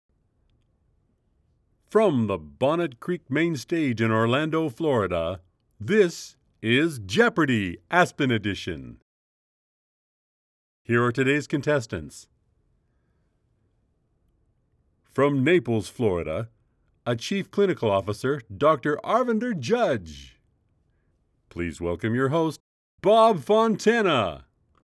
Corporate samples, including Lobby Background, Phone System Messaging and Prompts.
Corporate Retreat Event